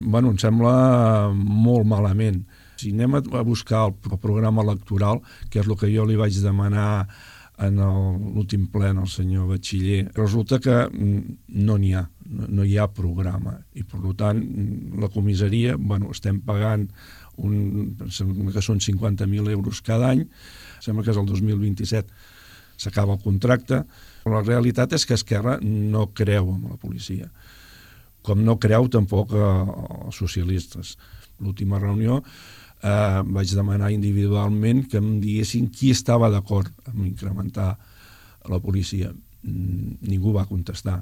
Josep Santamaria, exregidor de Seguretat Ciutadana, de Junts per Catalunya, va assegurar ahir al programa de Ràdio Palamós “Preguntes” que Bachiller no té un programa electoral en relació amb aquest tema.  Durant el seu mandat va mostrar com una opció, entre altres, construir la comissaria als terrenys destinats a un projecte d’habitatge cooperatiu que impulsa Sostre Cívic, a l’avinguda de Catalunya.